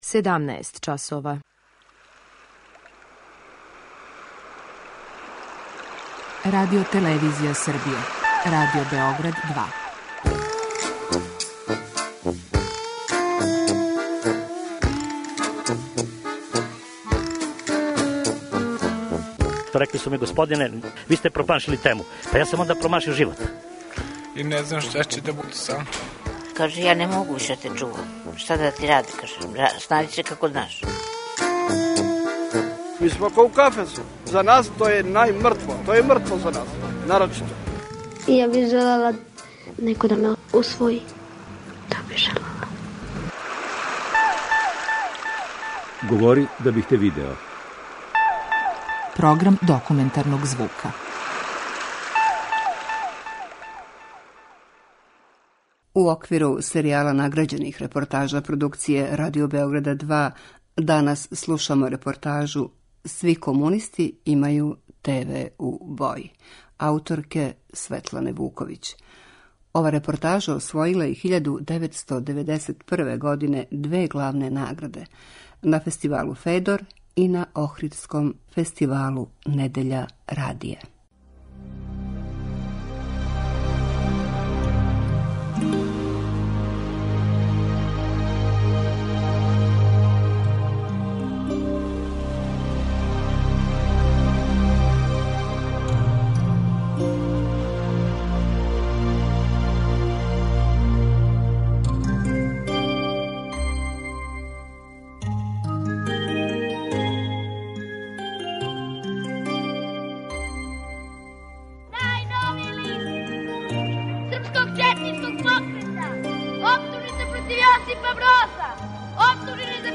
Документари програм: Серијал награђених репортажа